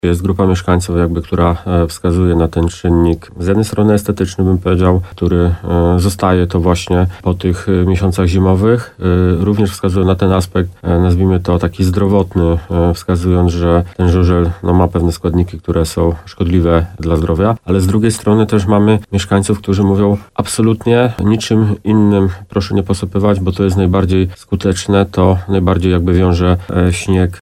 Jak zapowiedział w rozmowie Słowo za Słowo w radiu RDN Nowy Sącz burmistrz Tomasz Michałowski, niebawem będą organizowane spotkania w tej sprawie.